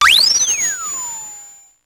Index of /90_sSampleCDs/300 Drum Machines/Electro-Harmonix Spacedrum
Drum20.wav